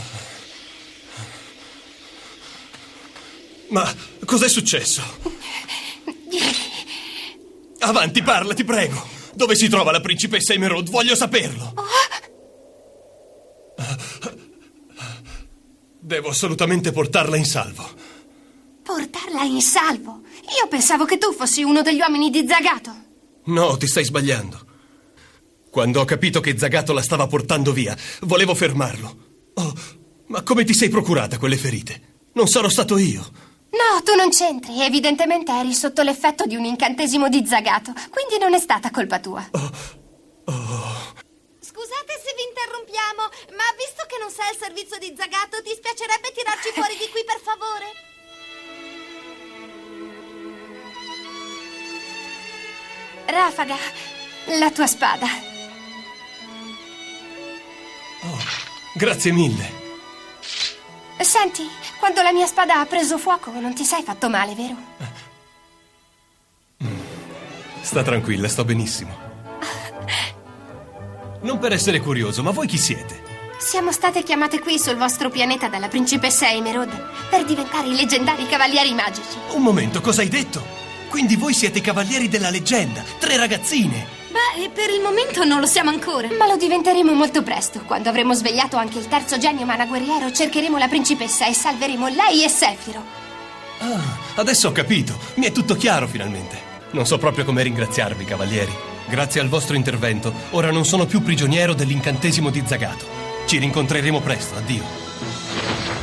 nel cartone animato "Una porta socchiusa ai confini del sole", in cui doppia Rafaga.